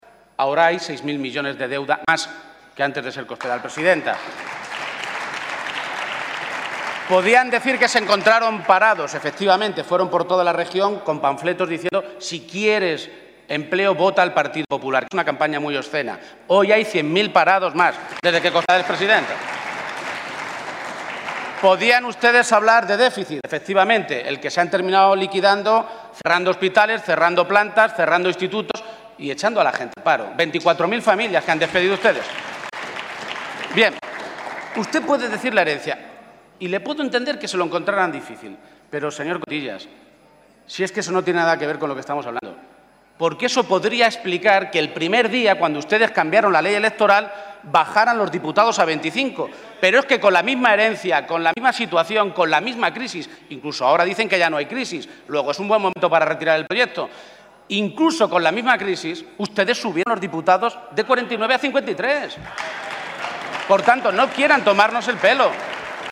Emiliano García-Page durante su intervención en el Senado
Audio Page-segunda intervención Senado 1